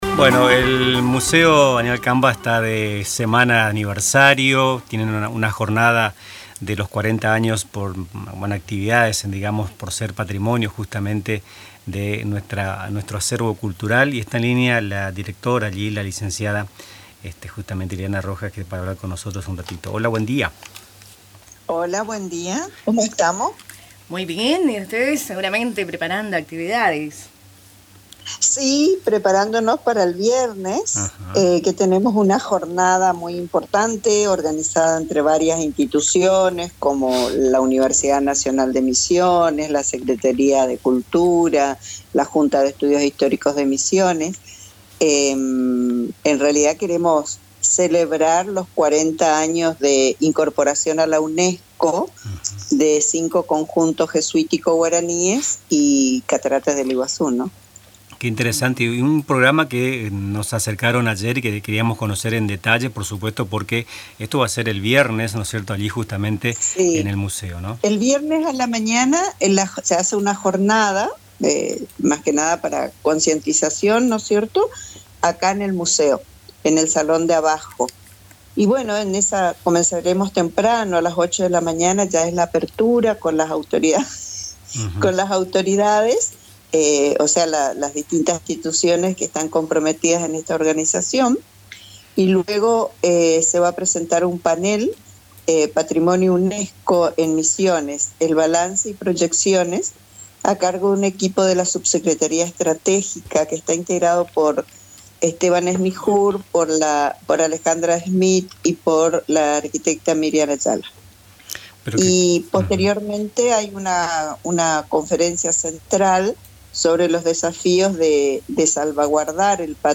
compartió detalles sobre la jornada en una entrevista con Radio Tupa Mbae.